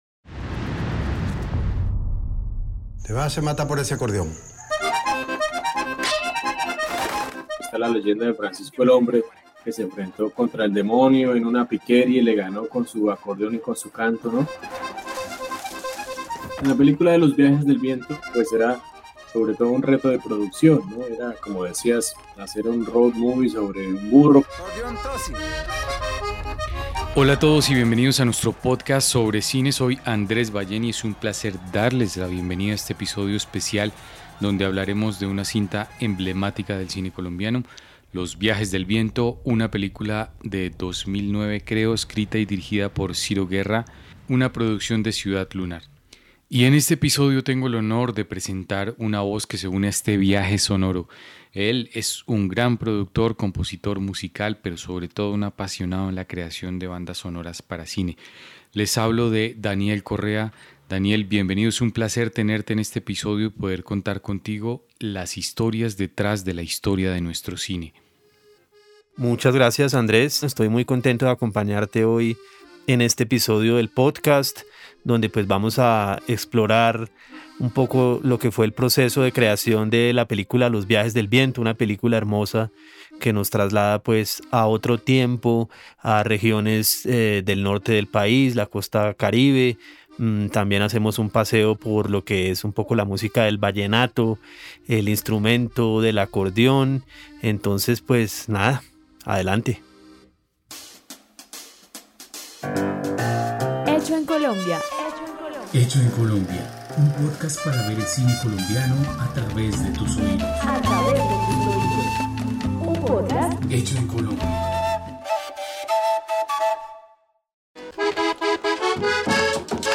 Estudio de grabación: Radiola Music Estudio.